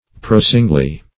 prosingly - definition of prosingly - synonyms, pronunciation, spelling from Free Dictionary Search Result for " prosingly" : The Collaborative International Dictionary of English v.0.48: Prosingly \Pros"ing*ly\, adv.